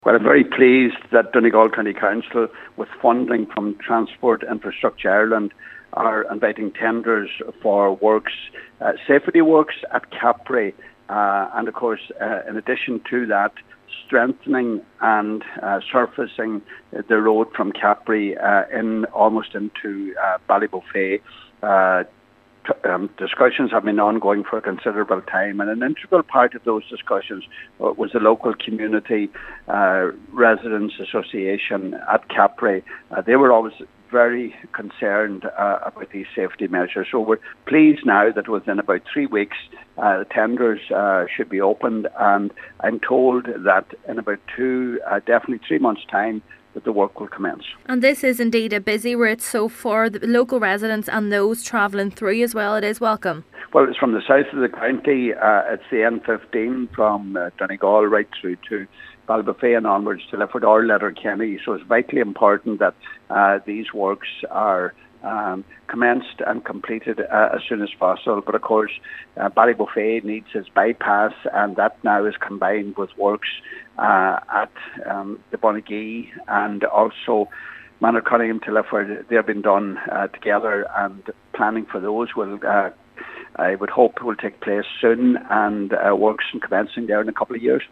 Donegal Deputy Pat the Cope Gallagher says, when complete these works will greatly enhance the safety of this busy route: